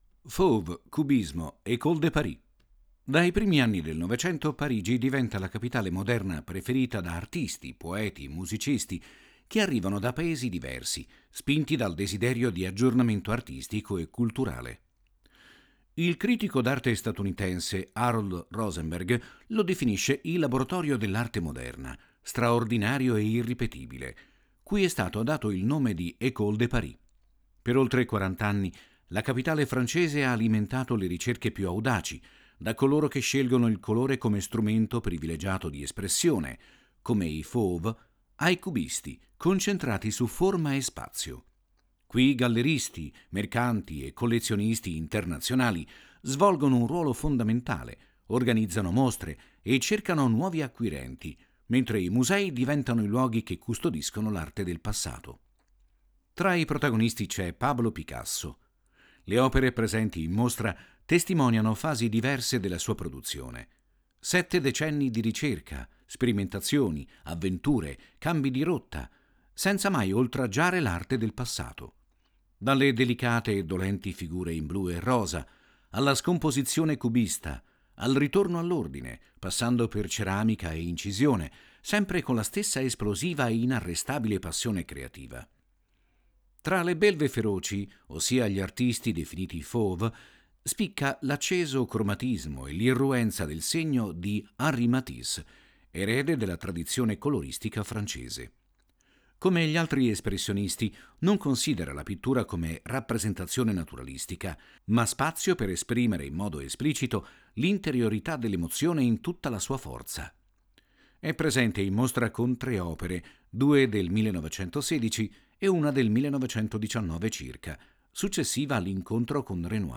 • 5 AUDIODESCRIZIONI DI SEZIONE che accompagnano il visitatore nel percorso espositivo, fruibili tramite QR code